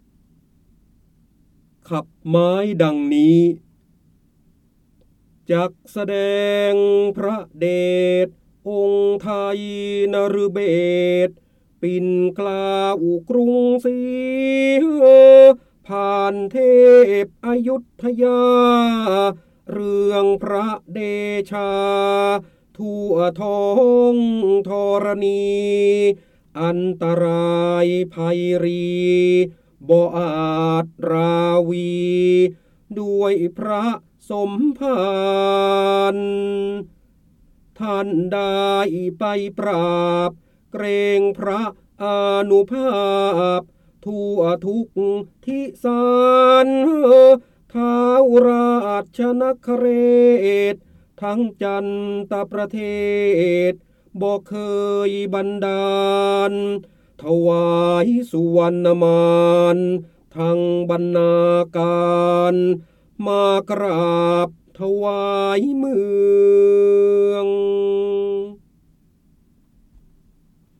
เสียงบรรยายจากหนังสือ จินดามณี (พระโหราธิบดี) ขับไม้ดังนี้
คำสำคัญ : ร้อยกรอง, การอ่านออกเสียง, พระโหราธิบดี, ร้อยแก้ว, พระเจ้าบรมโกศ, จินดามณี
ลักษณะของสื่อ :   คลิปเสียง, คลิปการเรียนรู้